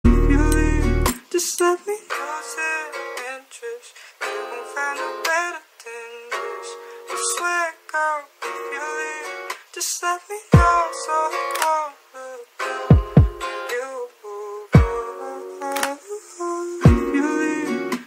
Sad Meme Losing Sound Buttons